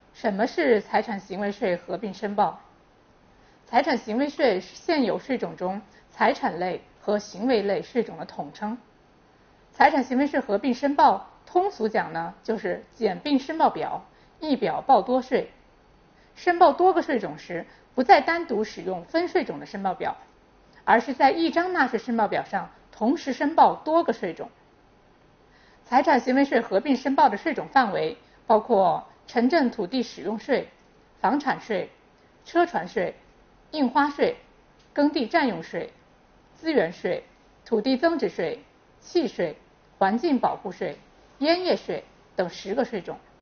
5月31日，聚焦6月1日起实施的财产和行为税合并申报，国家税务总局“税务讲堂”开讲。税务总局财产和行为税司副司长刘宜担任主讲，为纳税人解读新政策的意义和新申报表的填写方法。